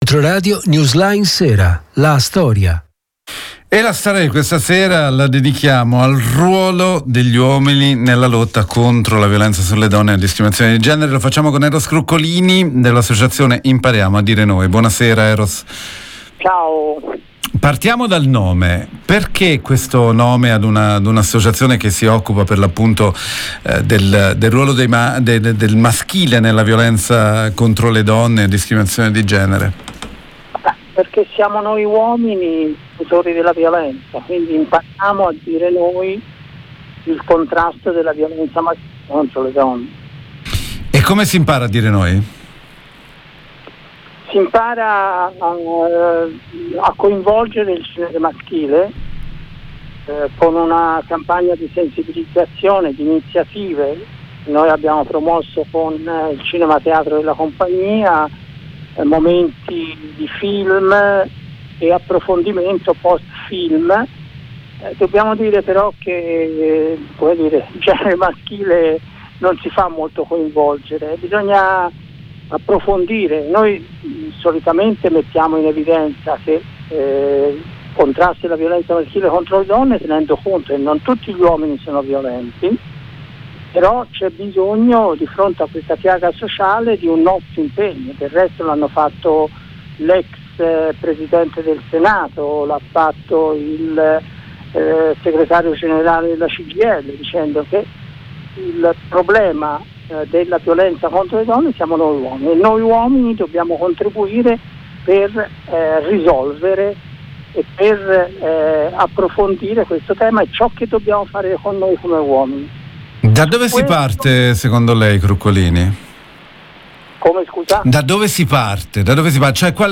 violenzaIntervista